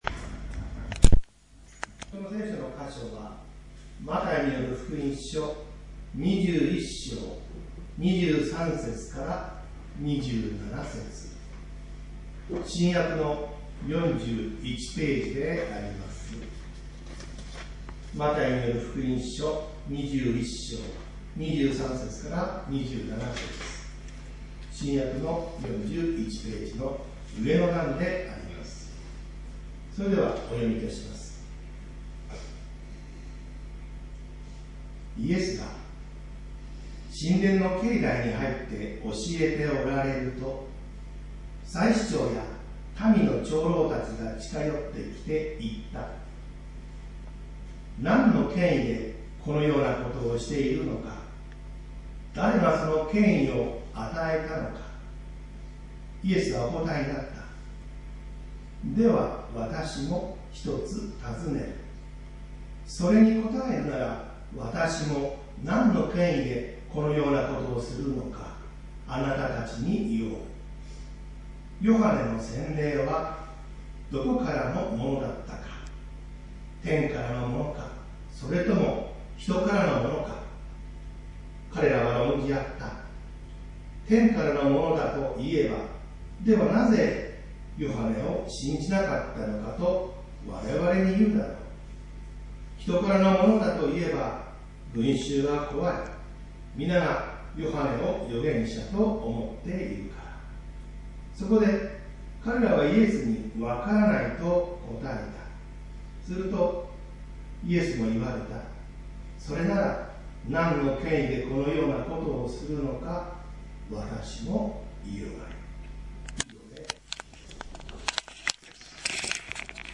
イエス様の権威 宇都宮教会 礼拝説教